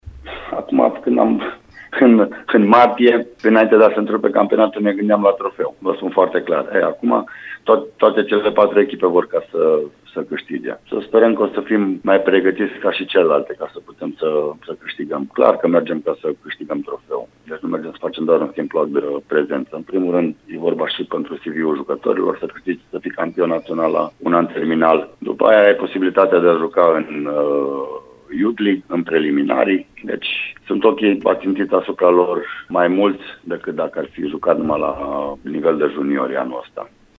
Într-un interviu pentru Radio Timișoara